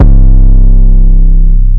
MURDA_808_SLIDES_C.wav